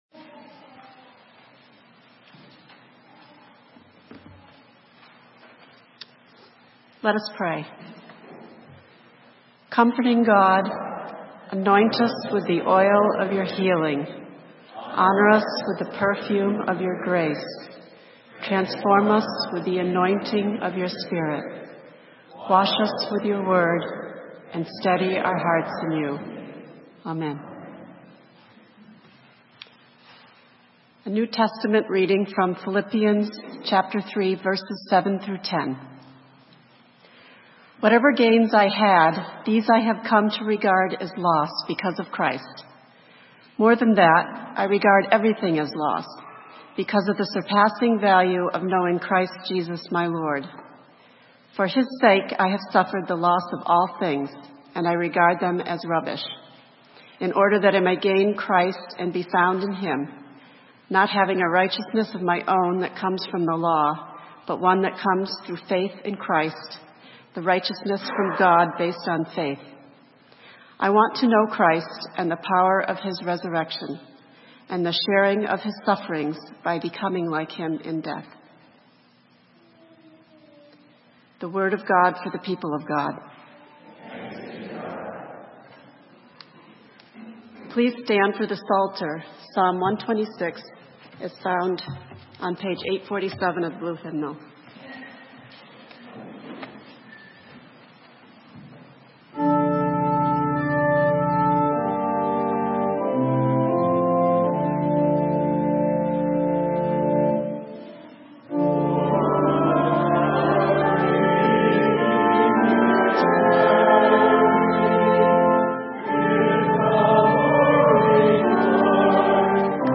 Sermon: Anointed in joy and sorrow - Saint Matthew's UMC